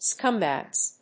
/ˈskʌˌmbægz(米国英語)/